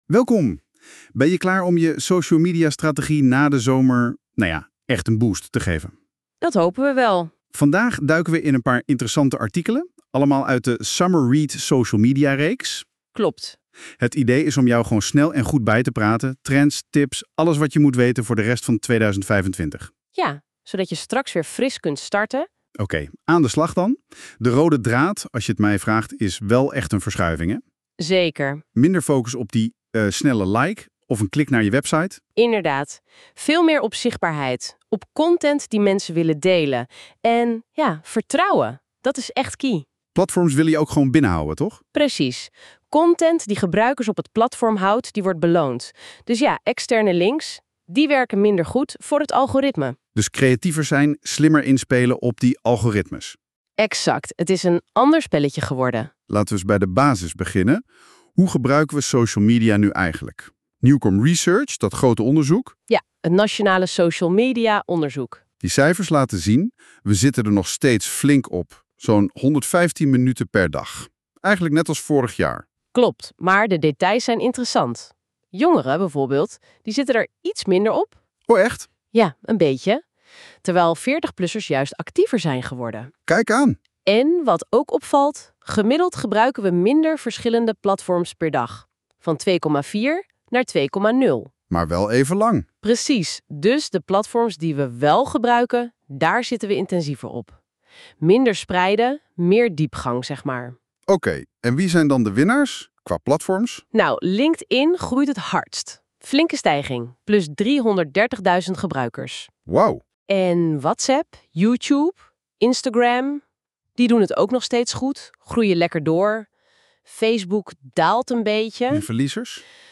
Laat je door 2 AI-hosts in 8 minuten bijpraten over deze summerread, gegenereerd door NotebookLM.